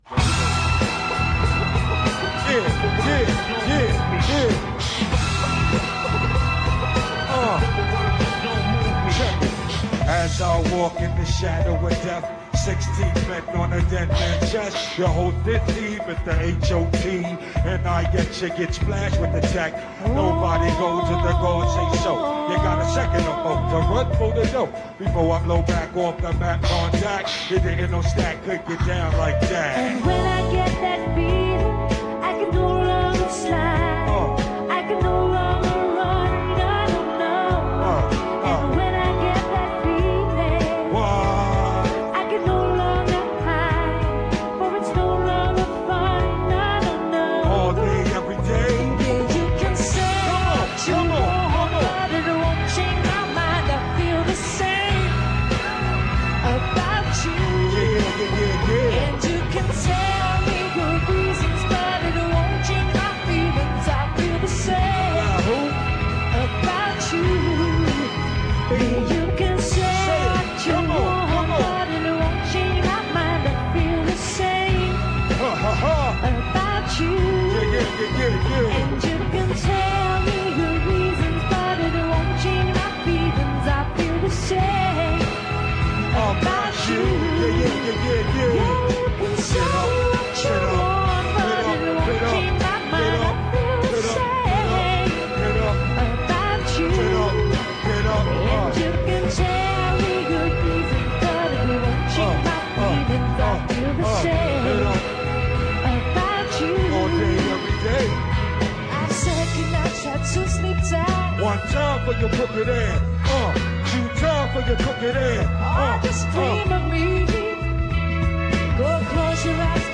live at the Brit Awards 98